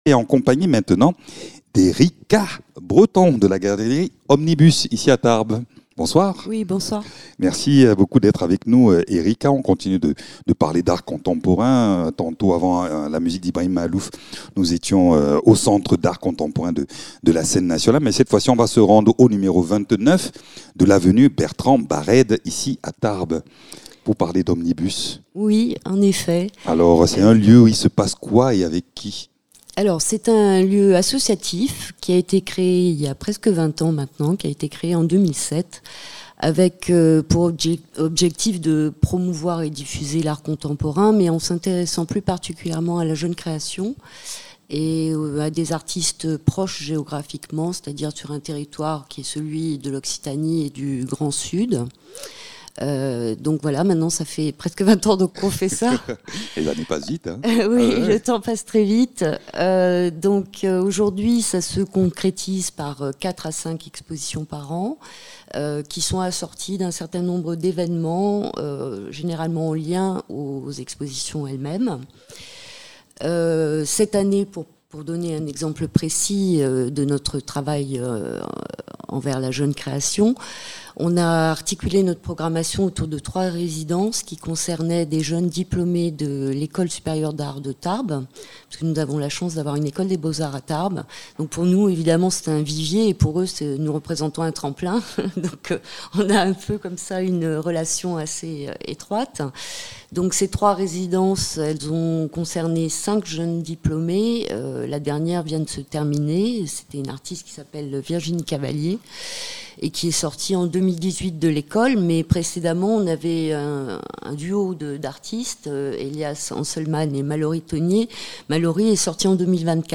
Dans cet entretien, découvrez comment la galerie façonne un espace accessible, ouvert et inspirant au cœur de la ville.